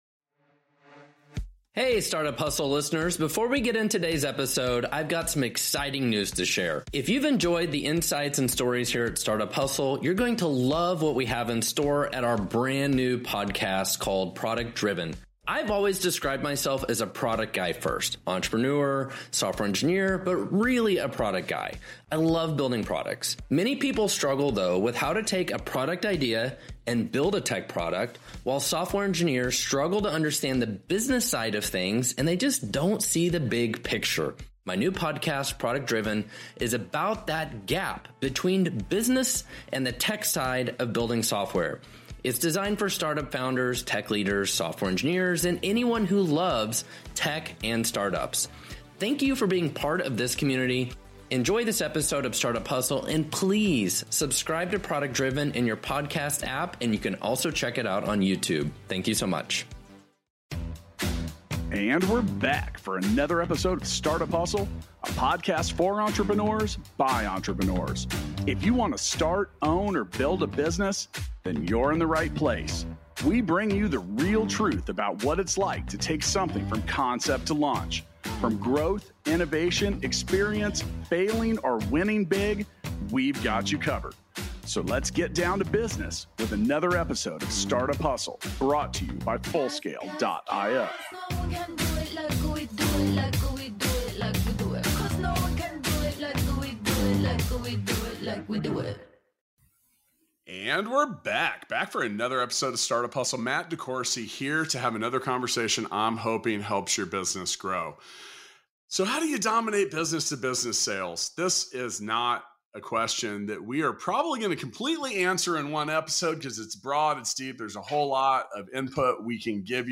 In this engaging conversation, gain insights from two accomplished sales professionals on why founders must excel in sales for their businesses to thrive. Tune in to discover strategies for consistently filling your sales funnel, the art of asking the right questions, and common pitfalls to avoid during prospecting.